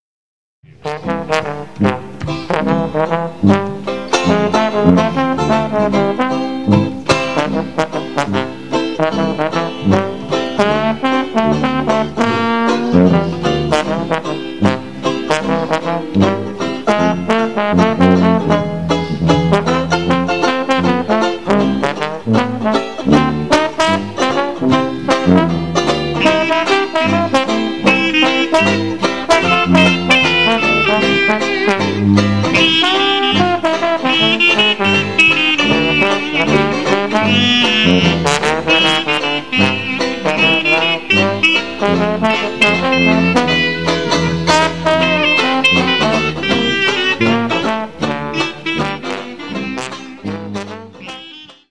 Label: Private mono recording – (recorded in the open air)